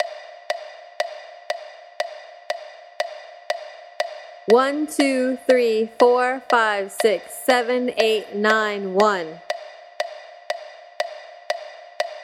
9/8 Example 1 Slow